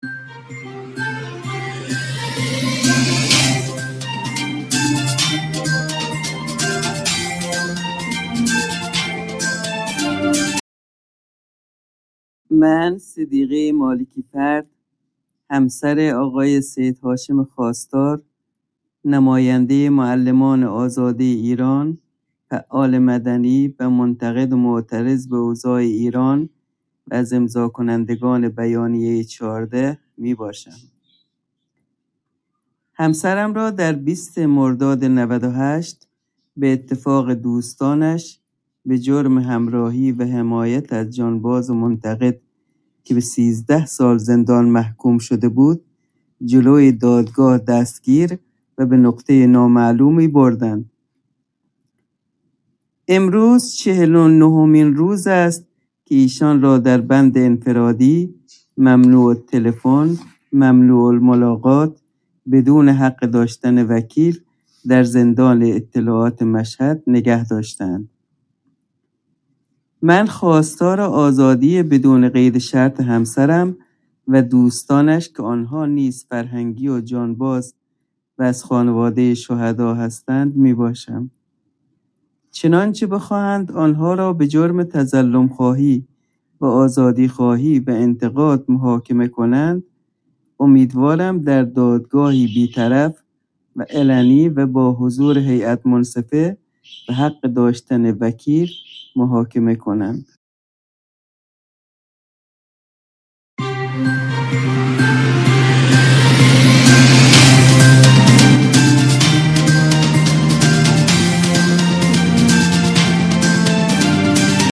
مصاحبه ای رادیویی